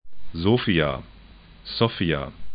'zo:fia